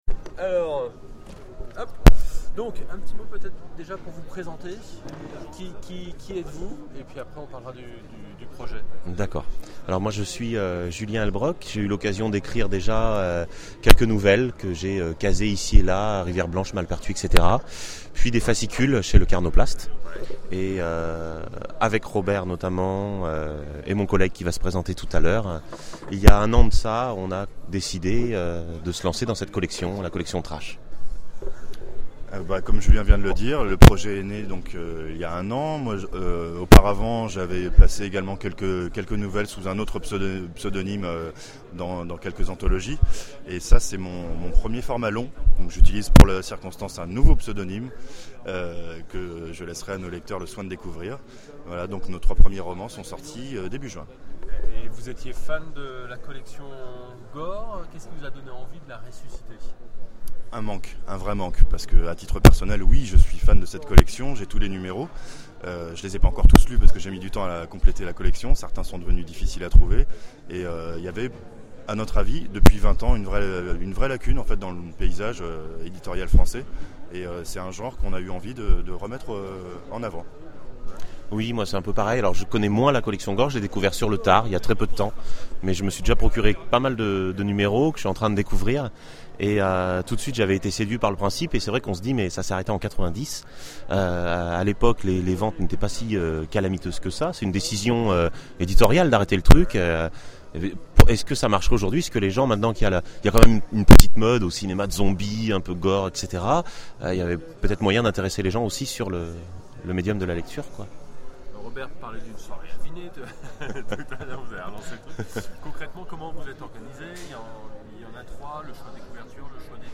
Interview Collectif Trash